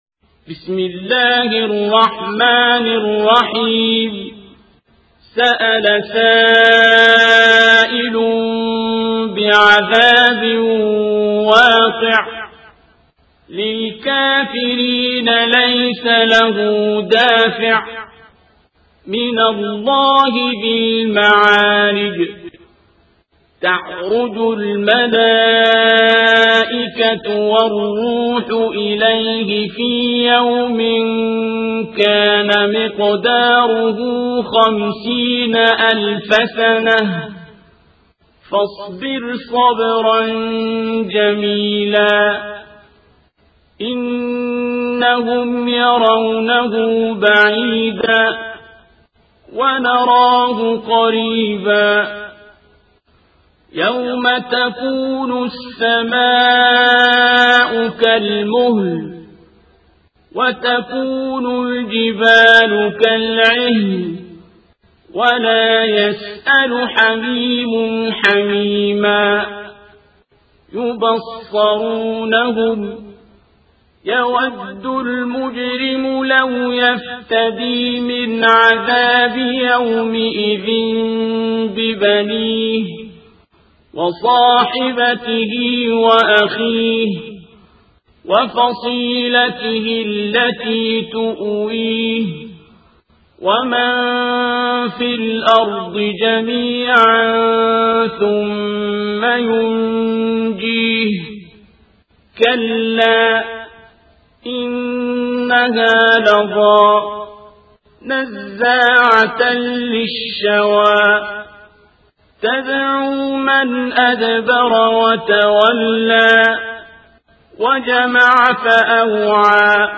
القارئ: الشيخ عبدالباسط عبدالصمد
تفاصيل : القرآن الكريم - سورة المعارج - الشيخ عبدالباسط عبدالصمد